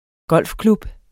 Udtale [ ˈgʌlˀfˌklub ]